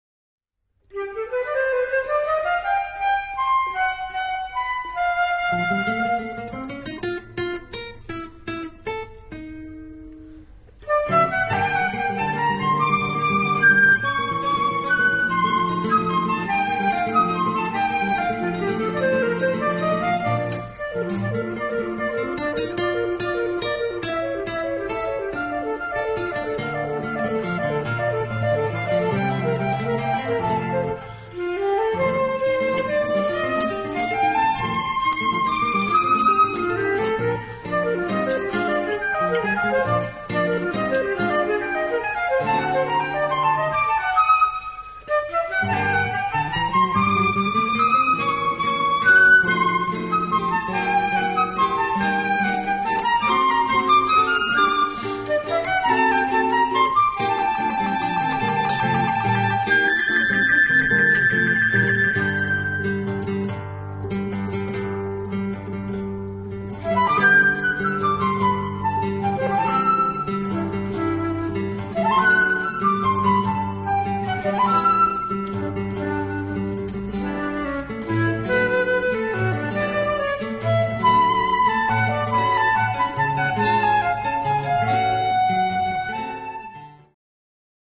guitar Two seminal pieces for guitar and flute duet.
Flute